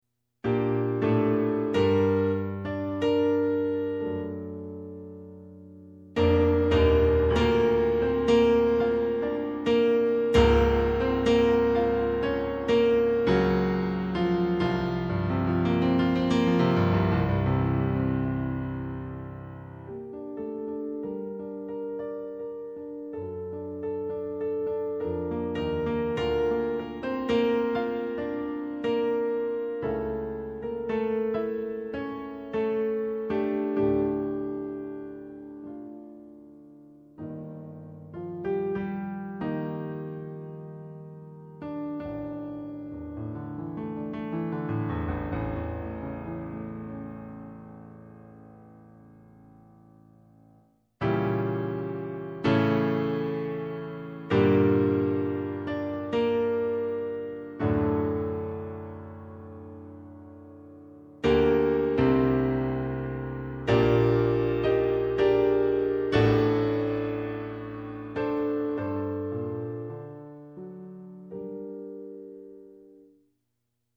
G minor